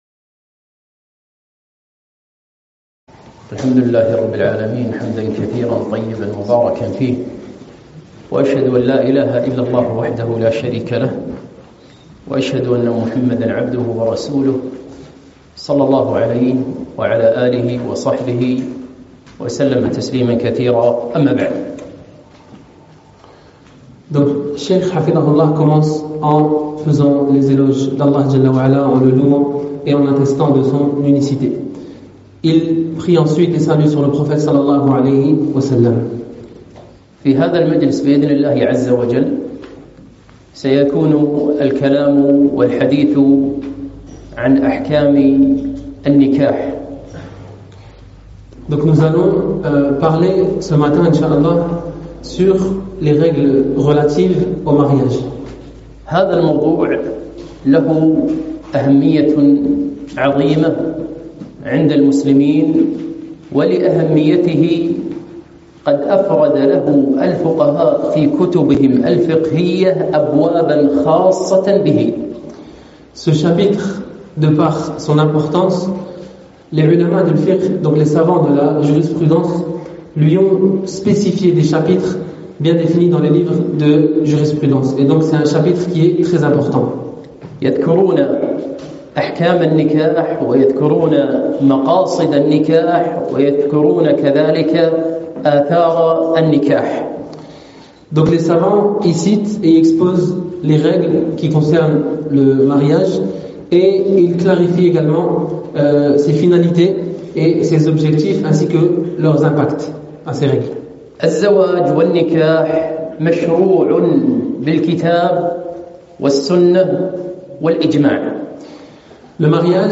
محاضرة - أحكام النكاح (مترجمة الفرنسية)